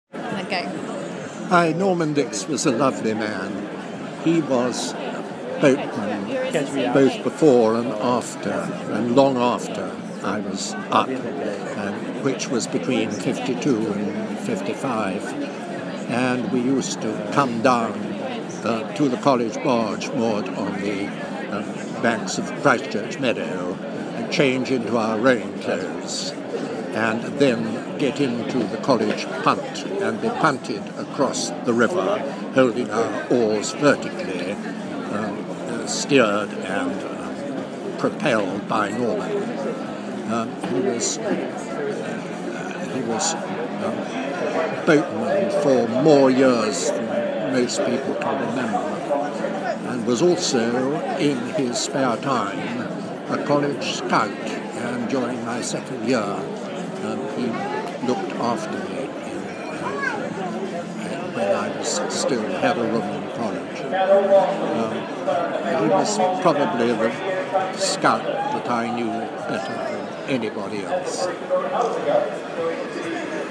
Interview
at Summer Eights 2013